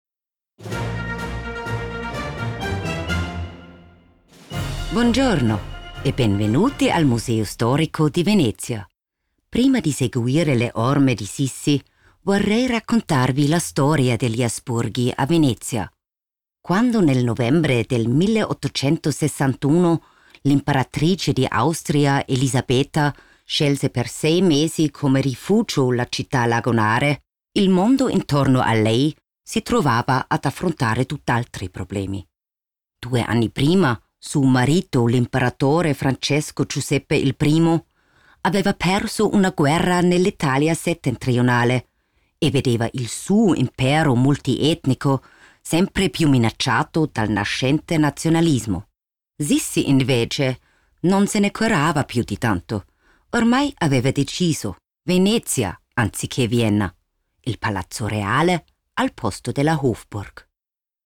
Audioguide – Italienisch:
Meine Frauenstimme hat Seltenheits- bzw. Wiedererkennungswert, da ich eine tiefe, markante und seriöse Klangfarbe habe.